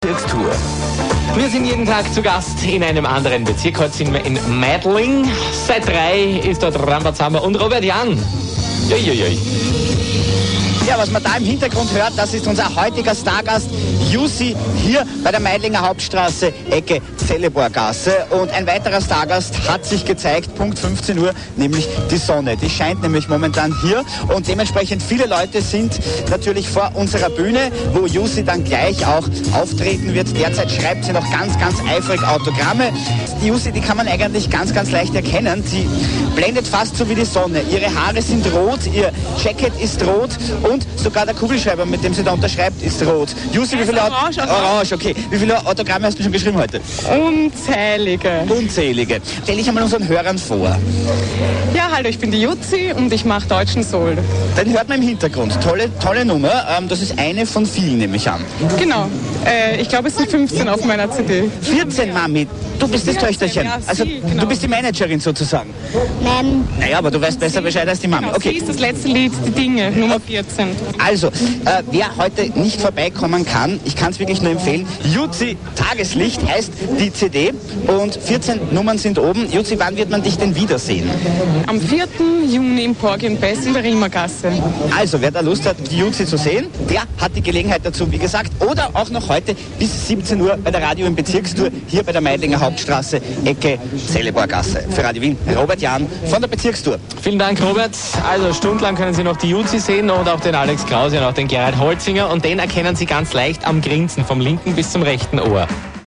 radiowien.mp3